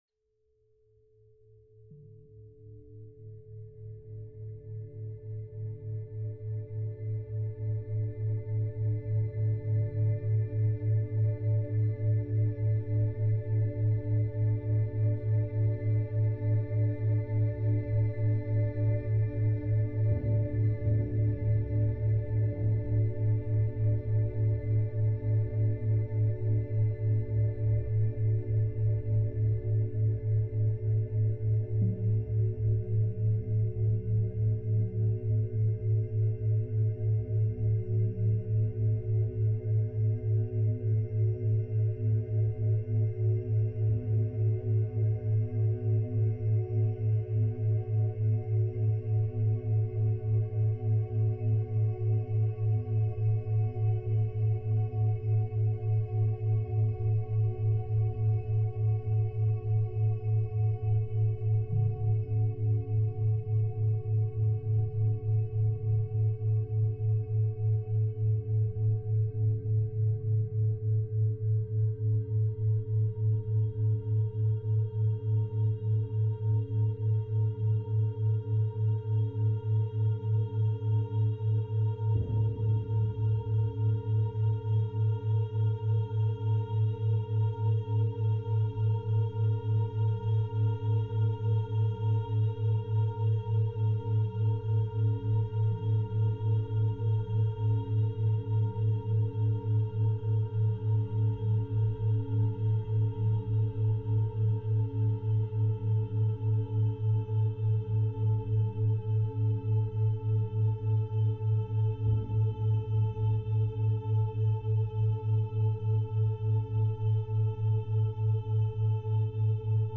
Deep Relaxation Meditation – 432 + 7 Hz for Mind-Body Healing